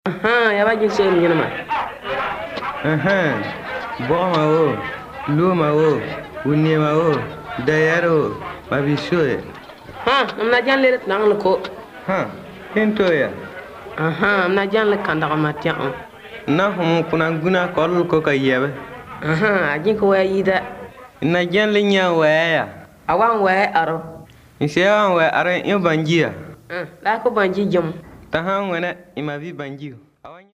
Words of Life recordings contain short Bible stories, evangelistic messages and songs. They explain the way of salvation and give basic Christian teaching. Most use a storytelling approach. These are recorded by mother-tongue speakers